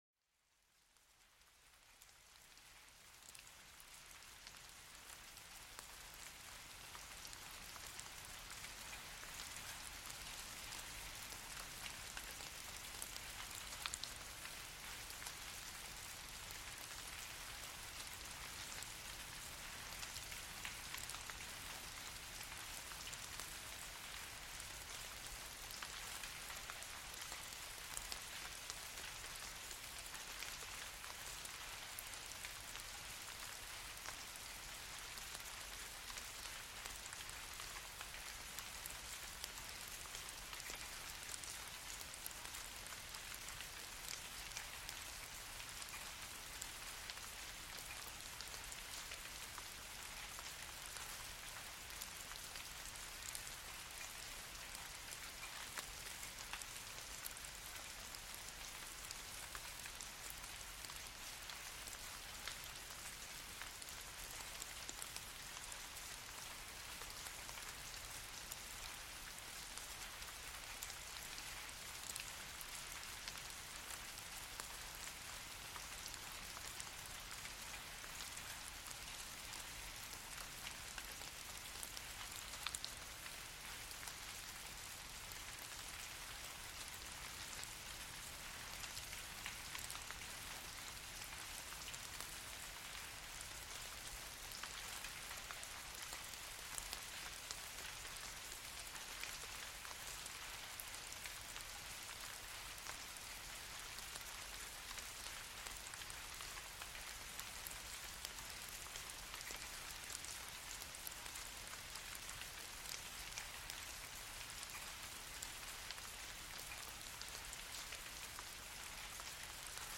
Lluvia suave en el bosque – Un sonido calmante para relajarse y dormir rápidamente
Sumérgete en el suave sonido de la lluvia cayendo sobre las hojas en un bosque tranquilo. Este sonido natural crea una atmósfera relajante, perfecta para calmar la mente y aliviar el estrés.